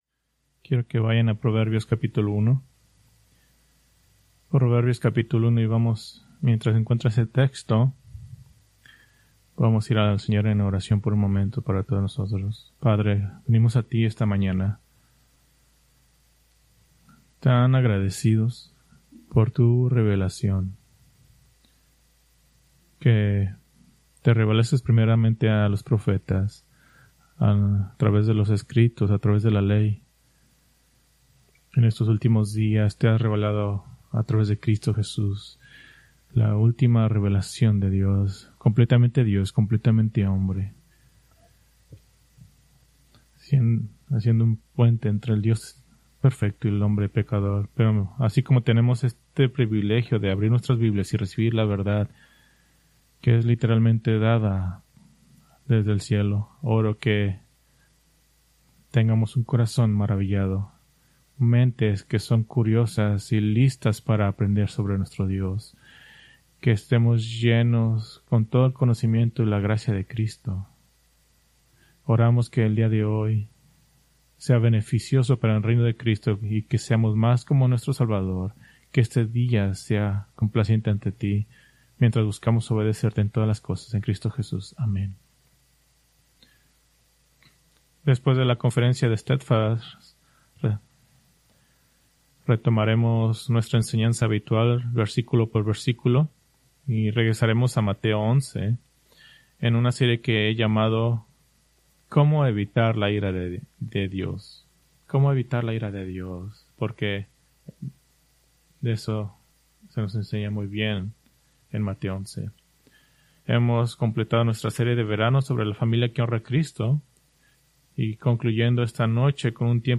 Preached September 7, 2025 from Escrituras seleccionadas